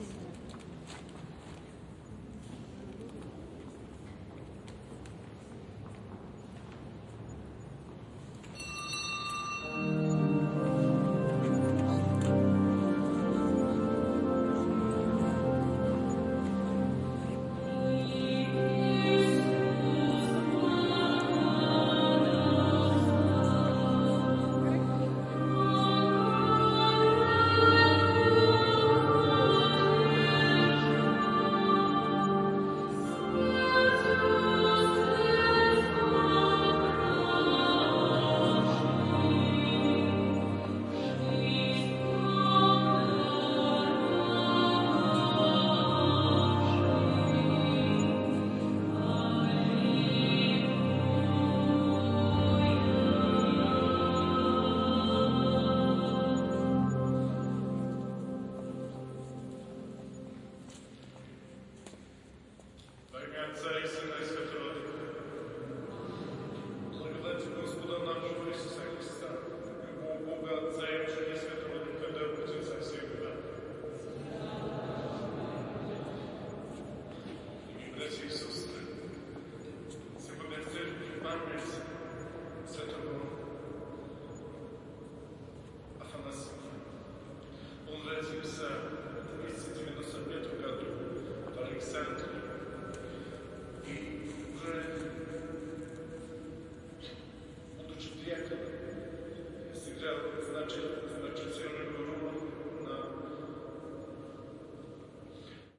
卡尔加里之声 " 吉他手路过
描述：吉他手路过：背景鸟声，吉他音乐走过， 日期：2015年11月22日时间：上午11:04记录编号：T90位置：公主岛公园走道，加拿大阿尔伯塔省卡尔加里技术：录制声音设备录音机和Rode NT4霰弹枪麦克风。
Tag: 歌唱 音乐 外面 吉他 弹奏 性质 歌曲 现场录音